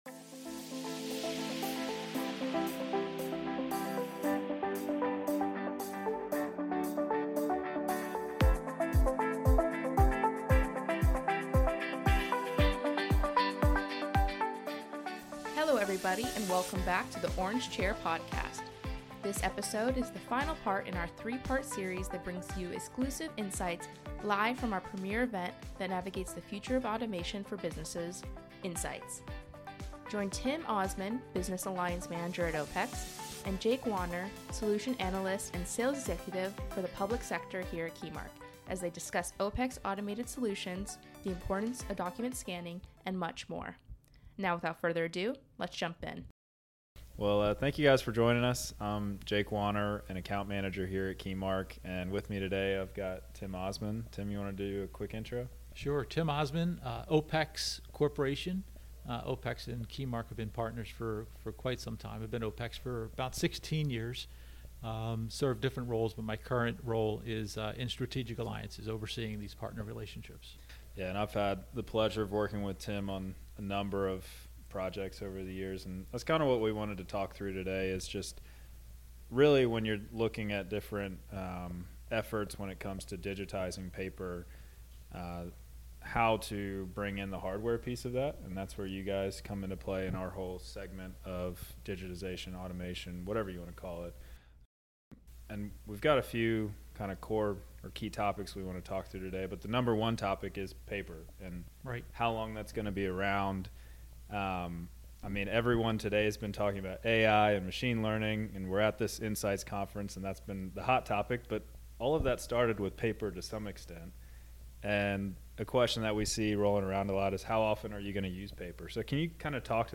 This episode is the final part of our three-part series that brings you exclusive insights live from our premier event that navigates the future of automation for businesses: Insights